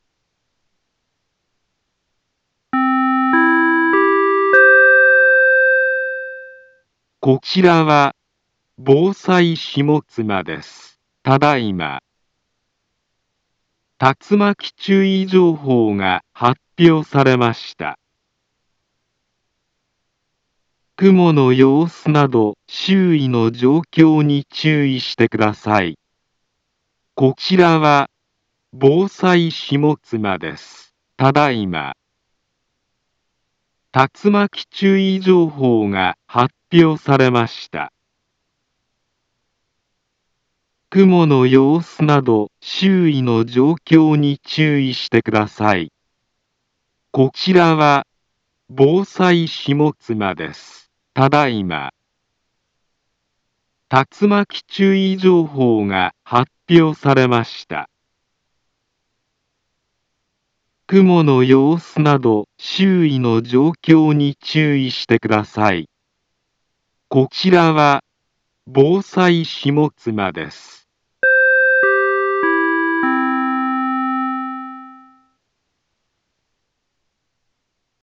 Back Home Ｊアラート情報 音声放送 再生 災害情報 カテゴリ：J-ALERT 登録日時：2025-09-18 15:54:45 インフォメーション：茨城県北部、南部は、竜巻などの激しい突風が発生しやすい気象状況になっています。